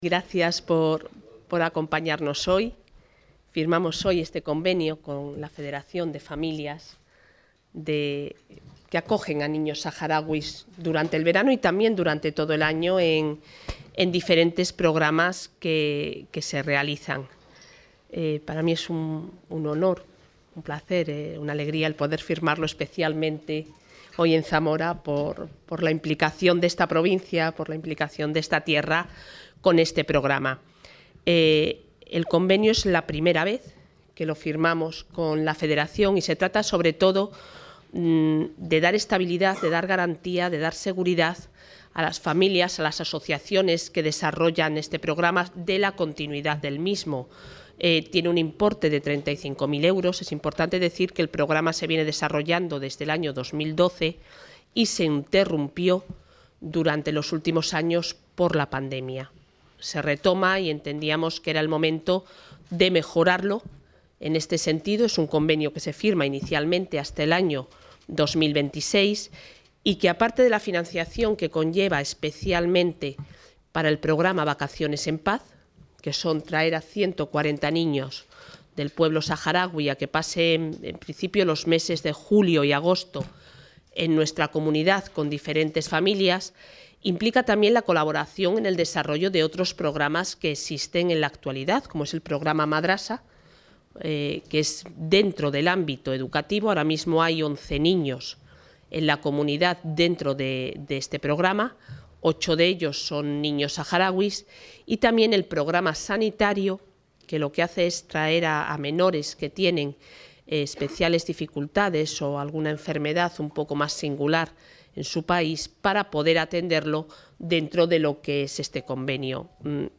Intervención de la consejera de Familia e Igualdad de Oportunidades.